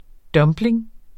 Udtale [ ˈdʌmbleN ]